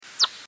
Lepakot ääntelevät lentäessään, mutta niiden ääni on niin korkeataajuista, että sitä ei yleensä ihmiskorvin kuule.
Koska me emme ääniä kuule, lepakoita havainnoidaan nykyisin tavallisesti nk. lepakkodetektorilla, joka on yliääniä kuultaviksi muuntava laite.
vesisiippa_janakkala_2009.mp3